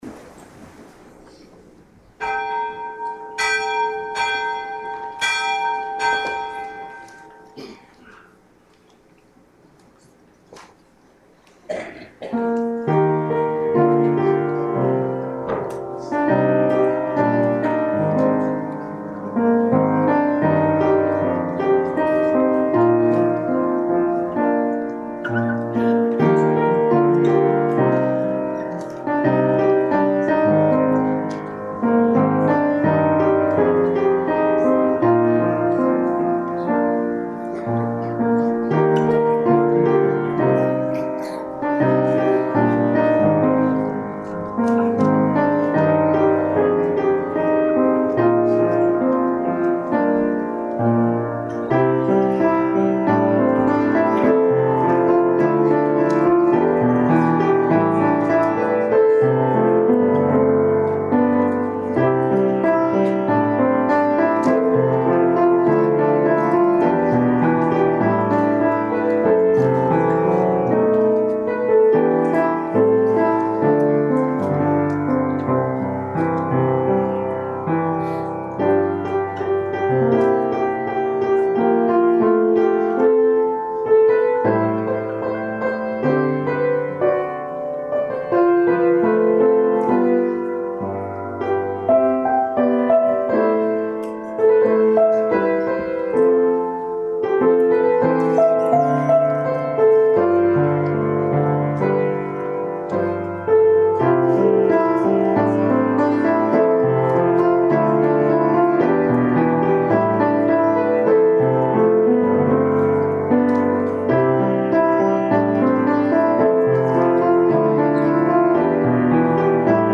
Prelude: My Grown Up Christmas List
Guitars: play background during the candle lighting and singing of Silent Night
Benediction & Choral Amen